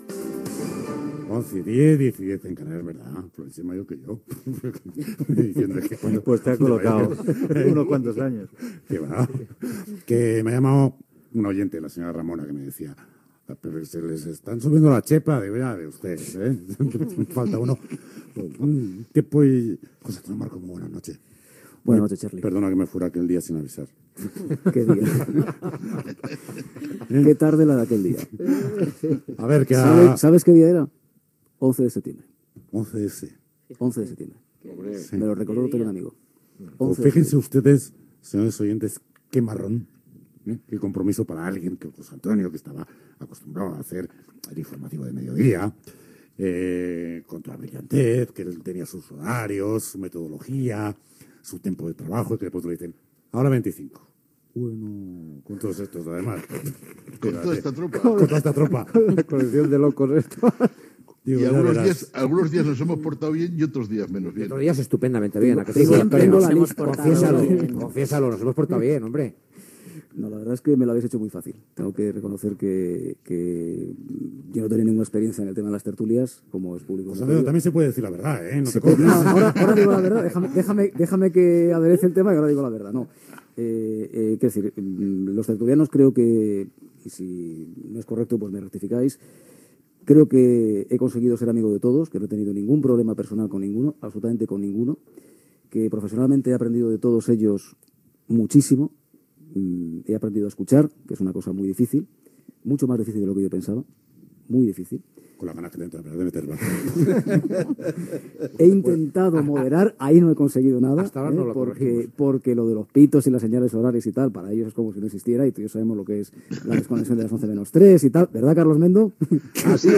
Hora, careta del programa, presentació del programa després d'estar Carlos Llamas fora dels micròfons 9 mesos degut a un càncer.
Informatiu